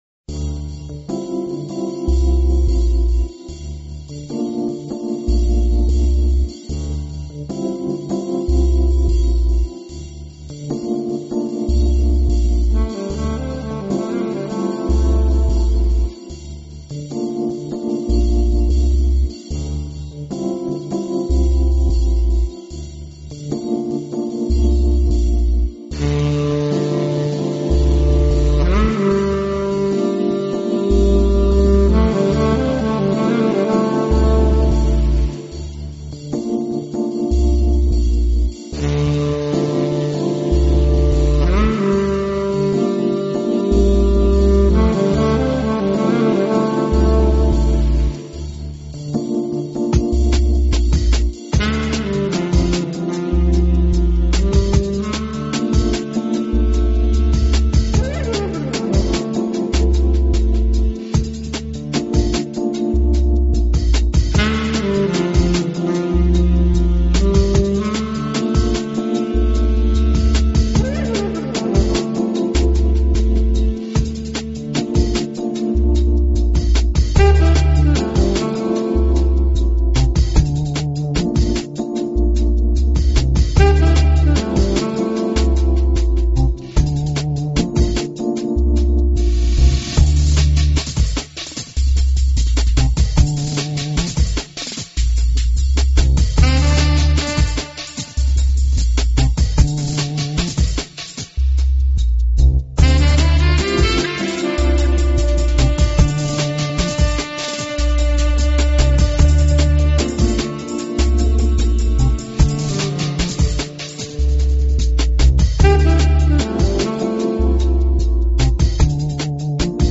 乐文化，Down Tempo是大部份的特色，范围涵盖了Jazz、Blue、Classic、
共同点都是带给人們Relax，还有Beautiful的感觉。